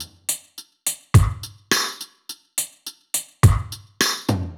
Index of /musicradar/dub-drums-samples/105bpm
Db_DrumKitC_Dry_105-02.wav